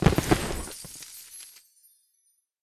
confetti_shot.ogg